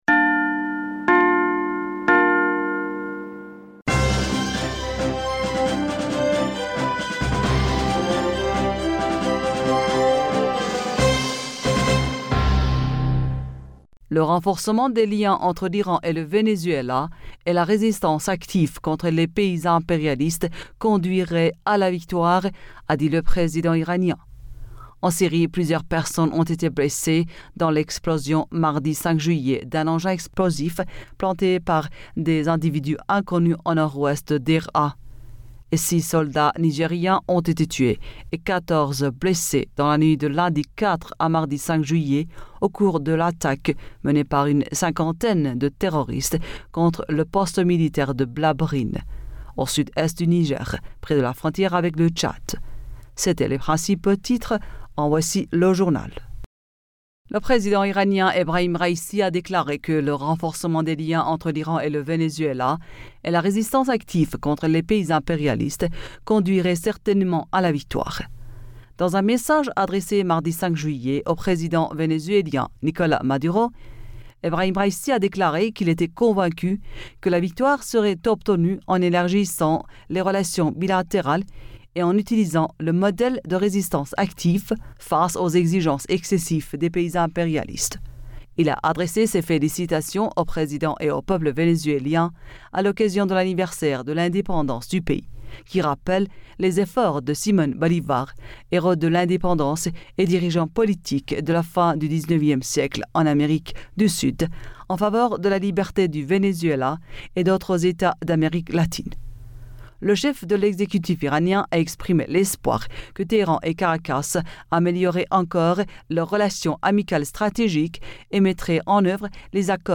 Bulletin d'information Du 06 Julliet